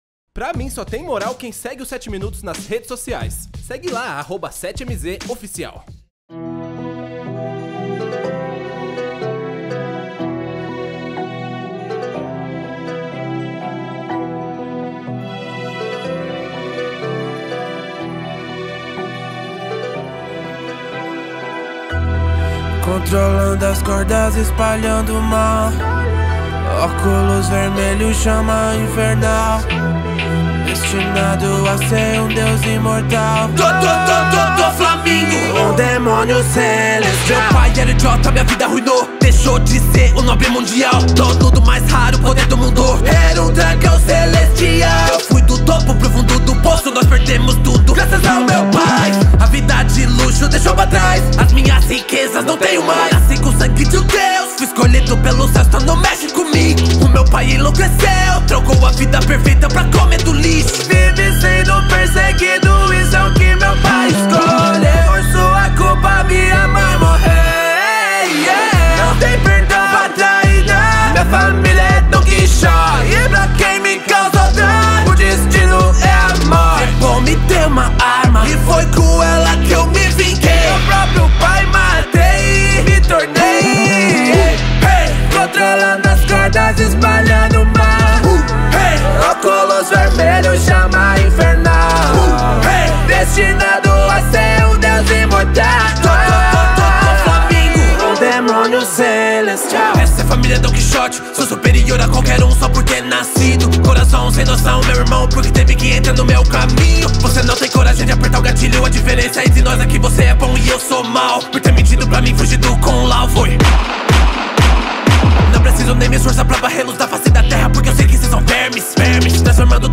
2024-08-22 19:56:43 Gênero: Rap Views